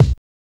LITTLE THUD.wav